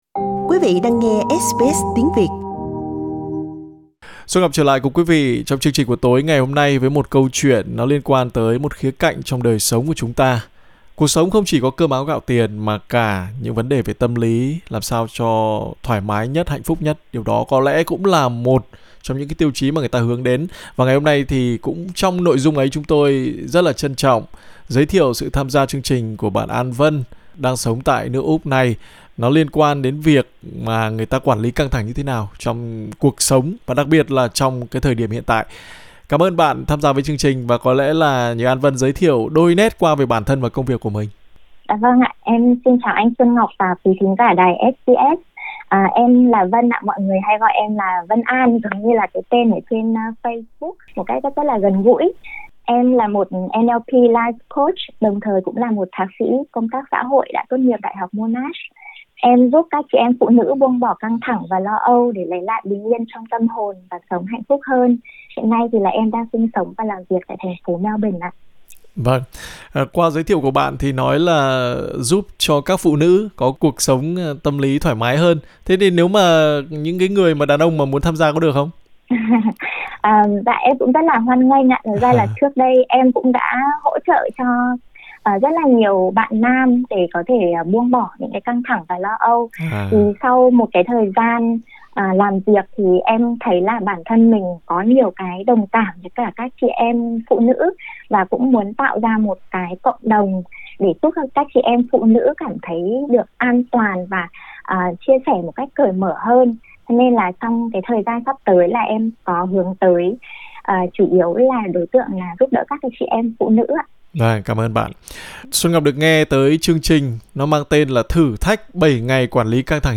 Trả lời phỏng vấn của SBS Tiếng Việt